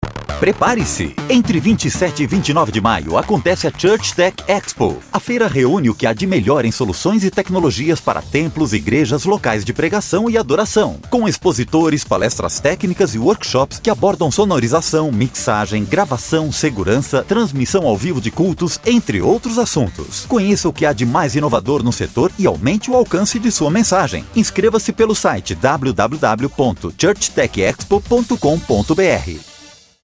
Masculino
Institucionais
Com o estúdio montado em casa, acústica bem cuidada e equipamentos de primeira linha, posso garantir o ótimo nível de qualidade técnica do trabalho.